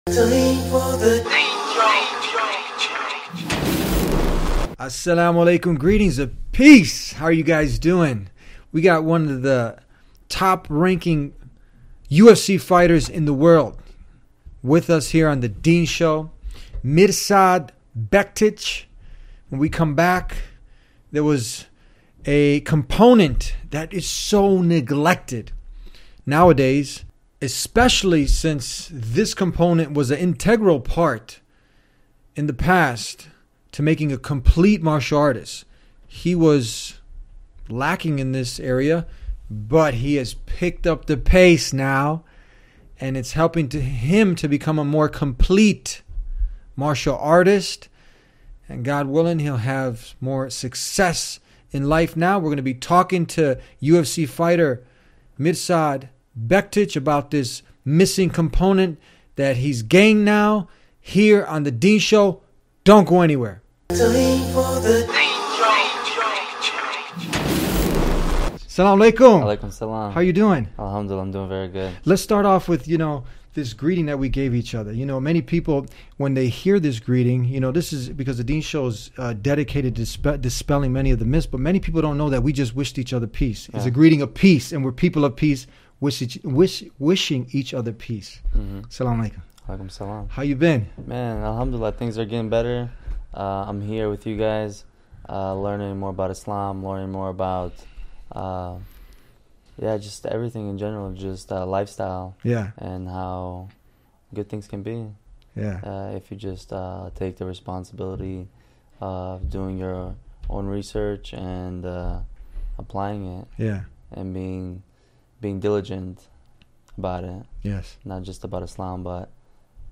In a powerful conversation on The Deen Show, Bektic opened up about how Ali’s unapologetic devotion to Islam awakened something deep within his own spiritual journey, and why that missing component — faith — transformed him into a more complete martial artist and human being.